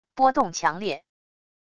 波动强烈wav音频